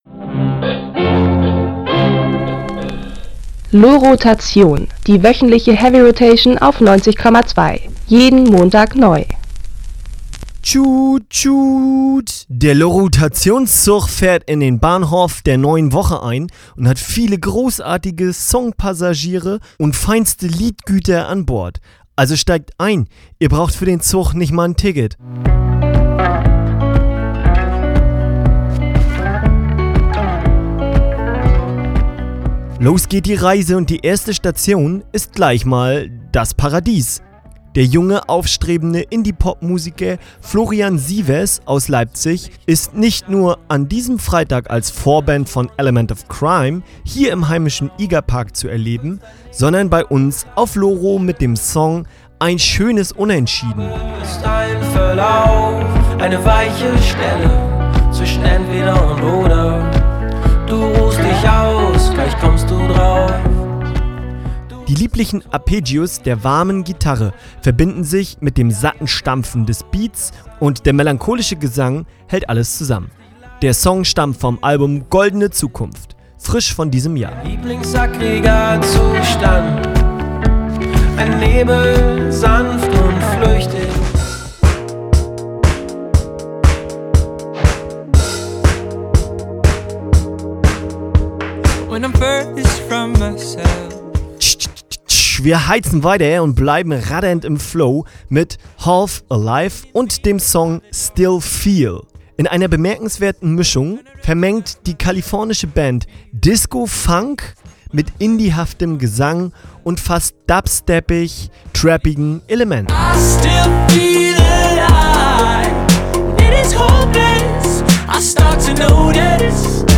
Mit entspannten Vibes und lockerem Groove singt Frontmann Louie Swain davon sich auf seine eigenen Kräfte zu verlassen.